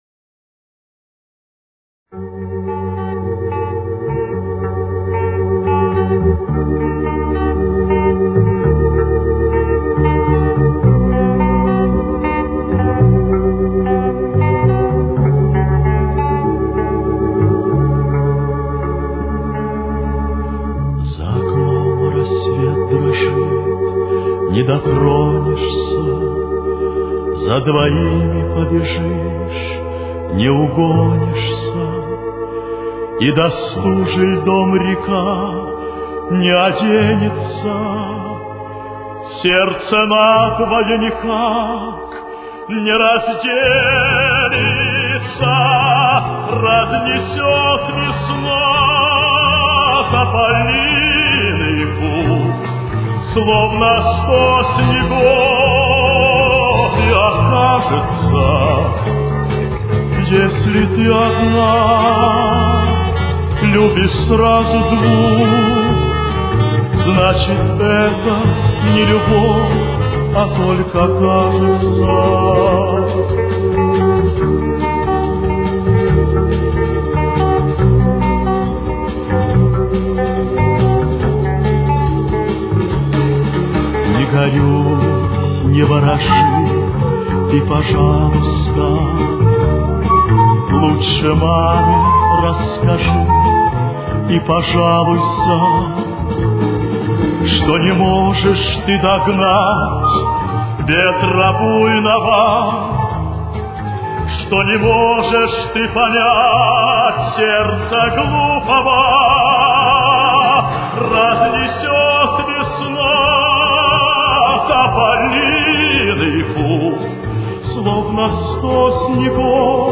Темп: 144.